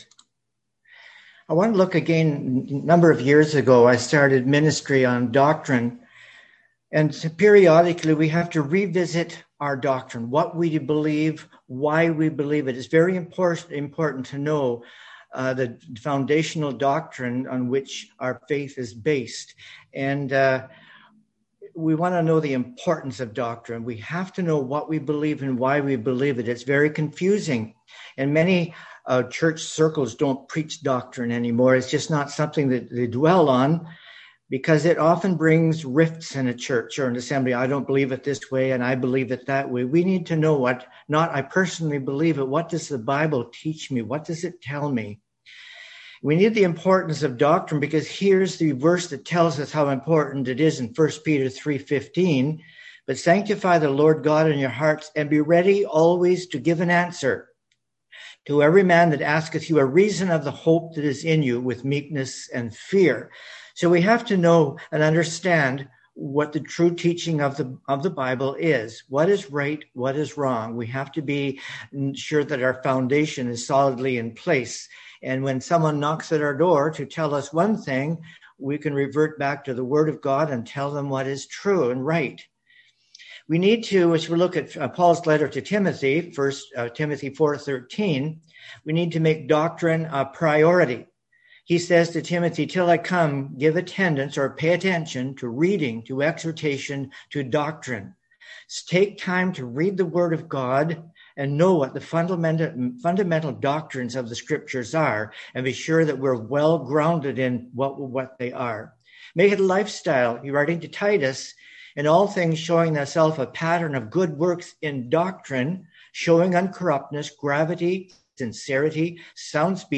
1Peter 3:15 & 1 Timothy 4:13 Service Type: Family Bible Hour A look at the Doctrine of Christ « He Is Not Here